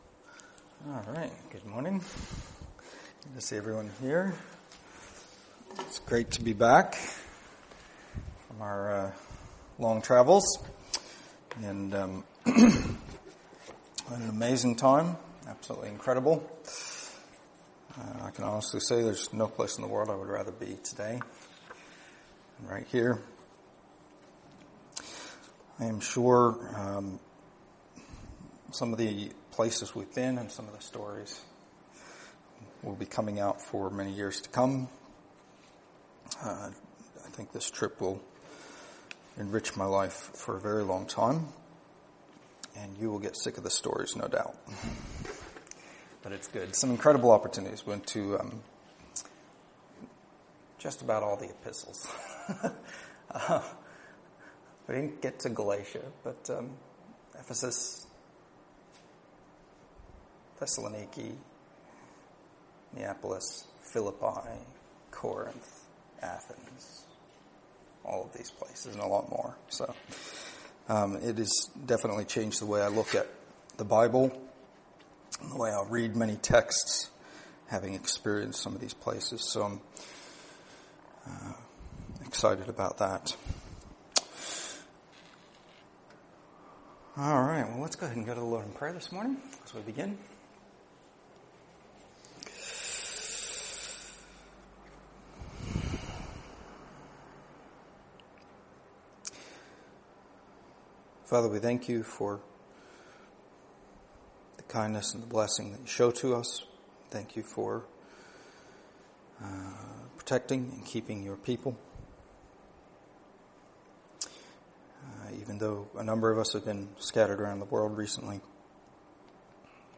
Download mp3 Previous Sermon of This Series End of series.